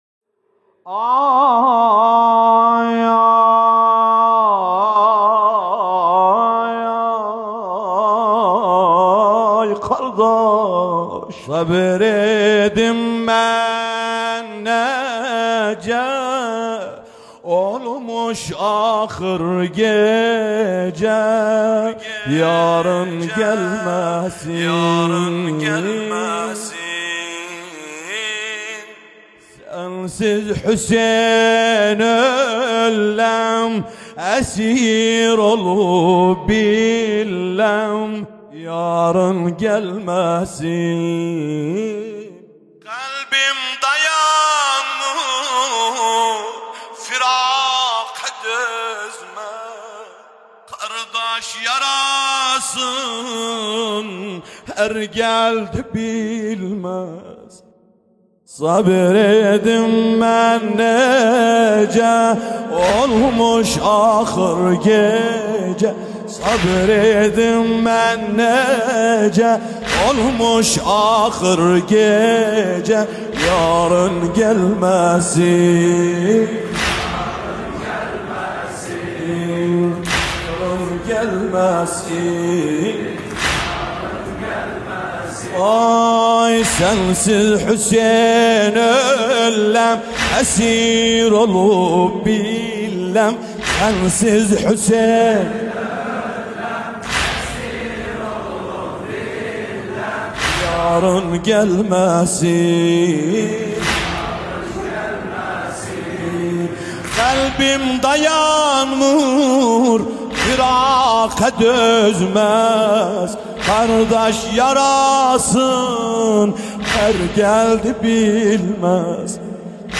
نوحه ترکمانی